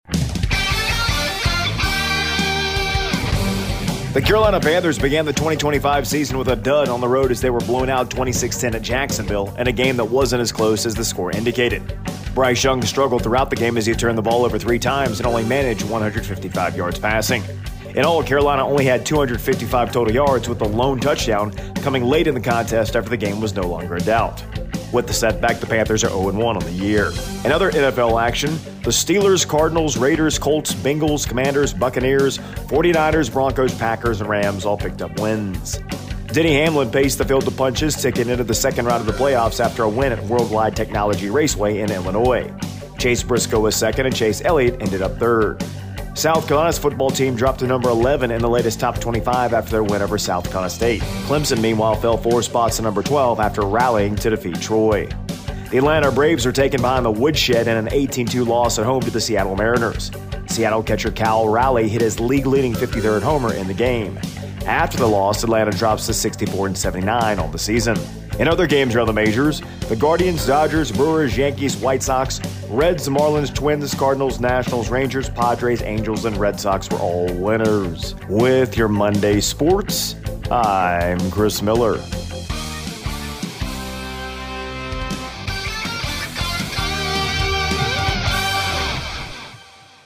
AUDIO: Thursday Morning Sports Report